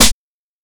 Snare (6).wav